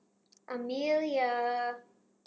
Amelia wakewords from 8 speakers of varying ages, genders and accents.